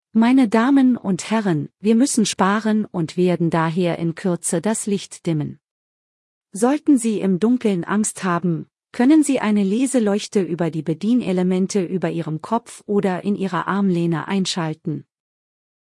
CabinDimTakeoff.ogg